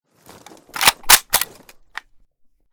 l85_unjam.ogg